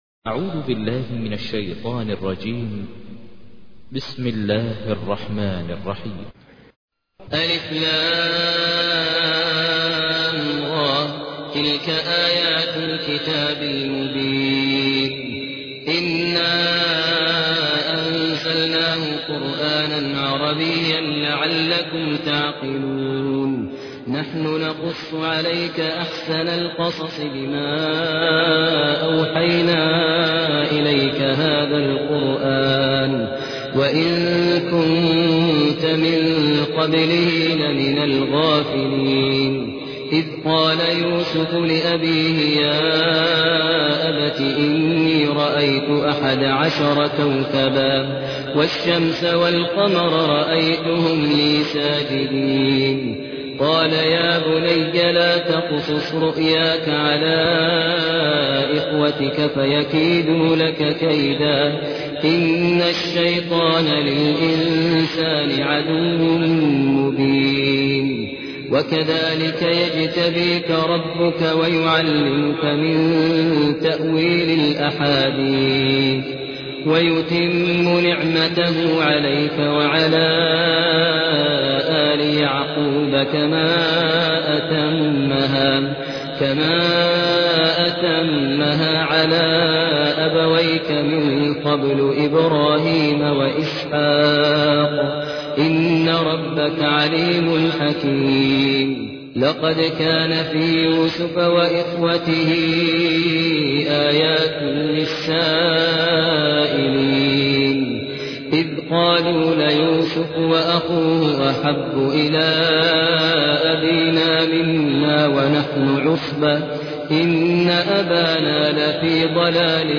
تحميل : 12. سورة يوسف / القارئ ماهر المعيقلي / القرآن الكريم / موقع يا حسين